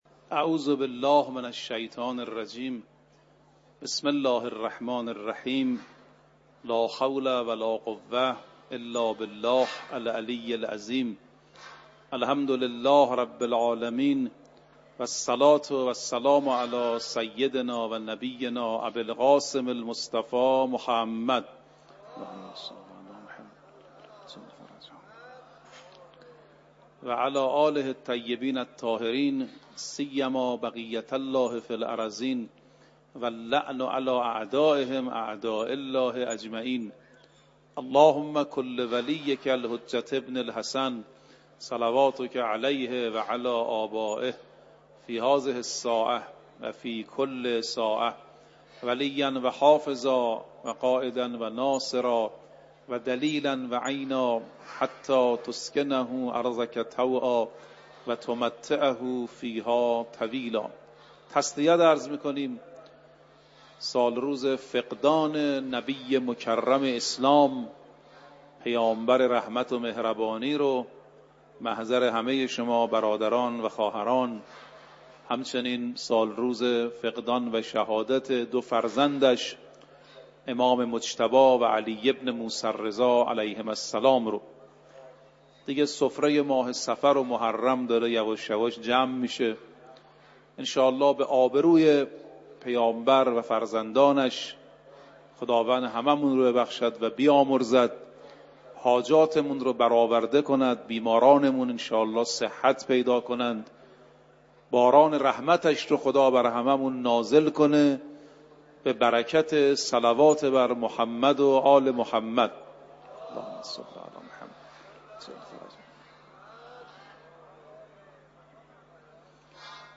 مدت سخنرانی